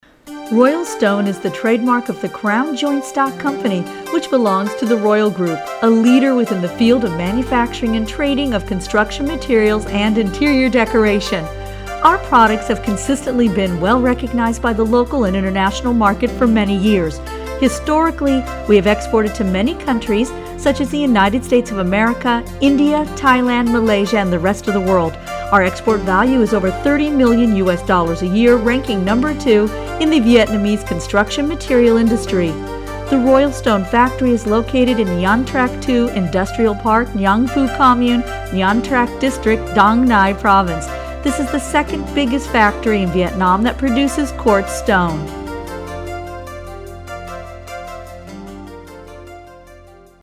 I have a warm, upbeat professional voice that can enhance your project.
Sprechprobe: Industrie (Muttersprache):